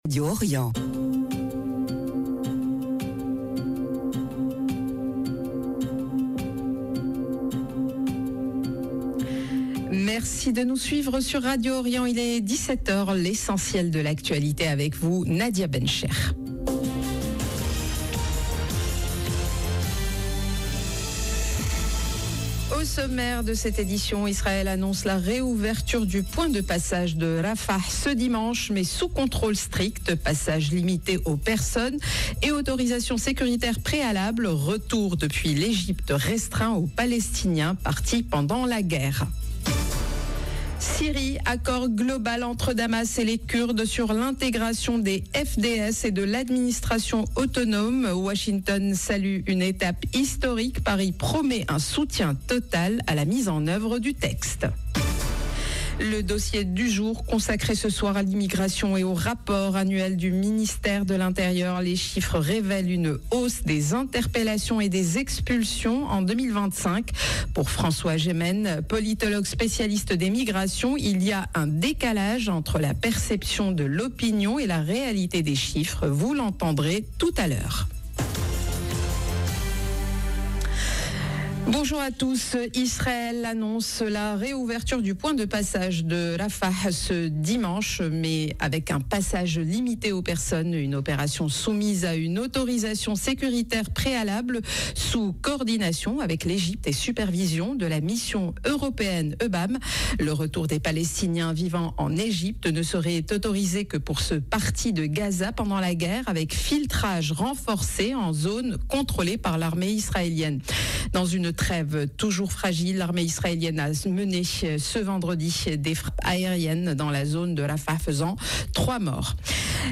Journal de 17H Au sommaire: Israël annonce la réouverture du point de passage de Rafah ce dimanche, mais sous contrôle strict : passage limité aux personnes et autorisation sécuritaire préalable.